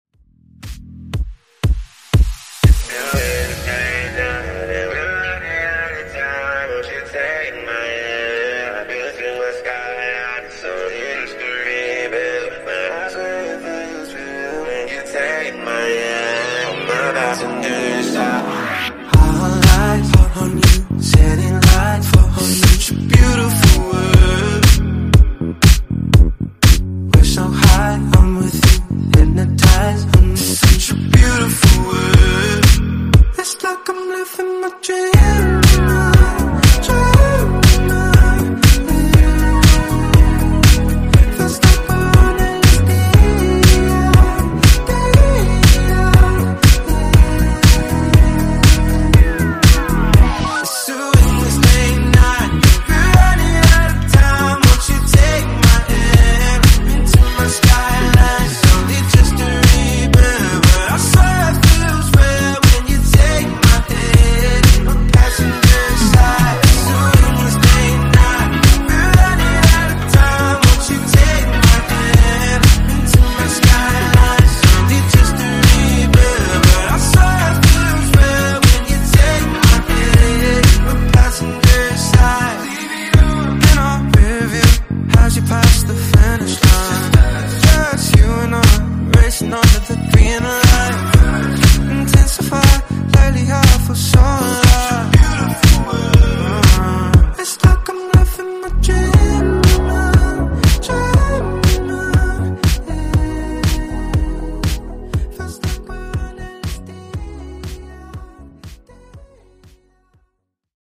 Genre: RE-DRUM Version: Clean BPM: 120 Time